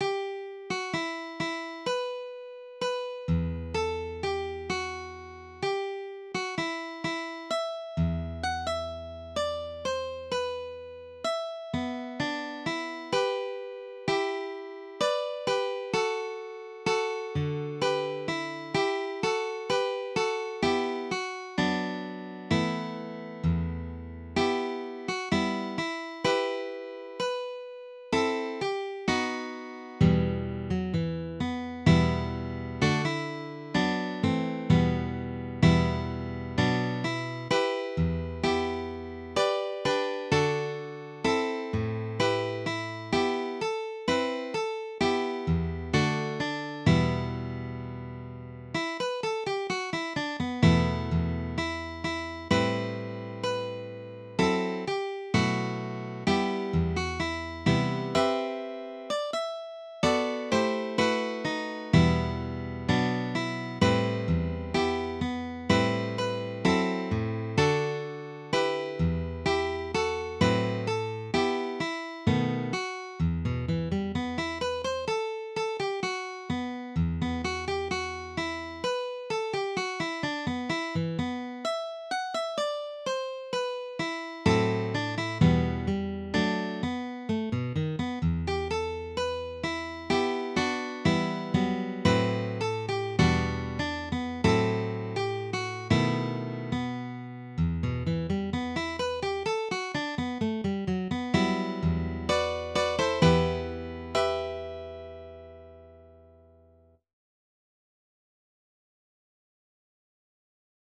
DIGITAL SHEET MUSIC - FLATPICK/PLECTRUM GUITAR SOLO
Sacred Music, Preludes, Graduals, and Offertories
Dropped D tuning